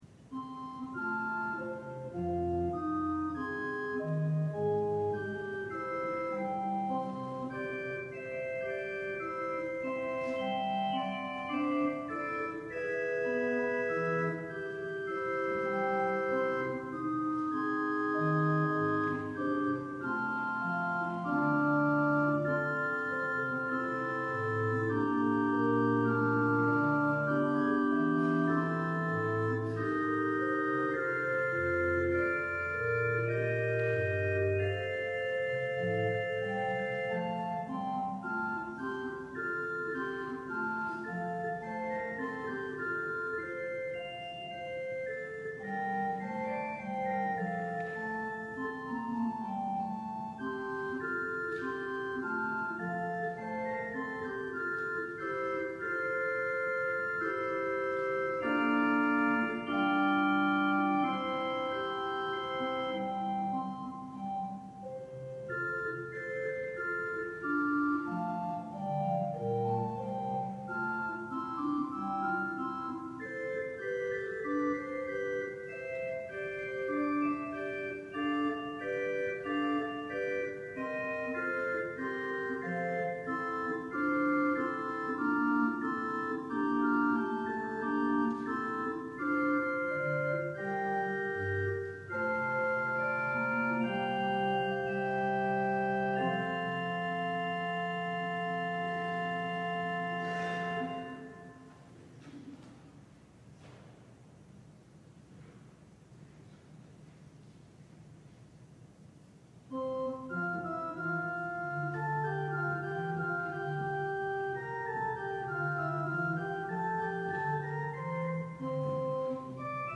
LIVE Evening Worship Service - Wanted: Harvest Workers